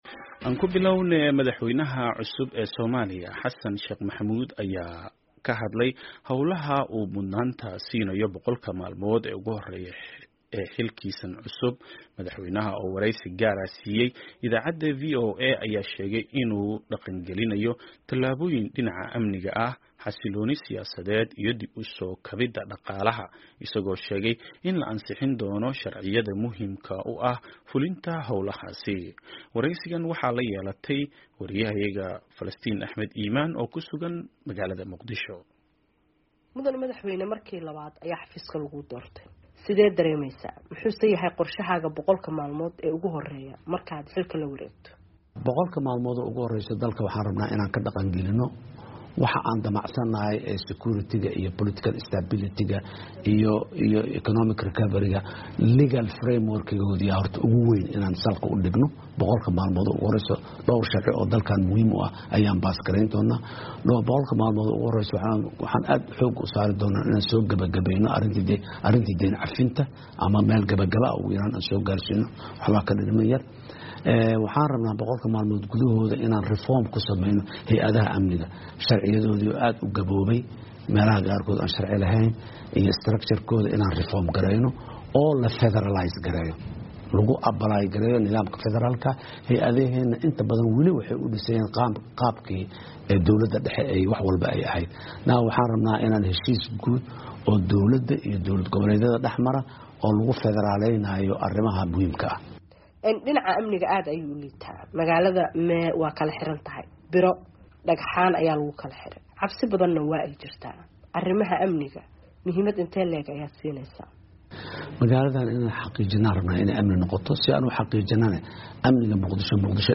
Wareysi: Madaxweyne Xasan Sheekh Maxamuud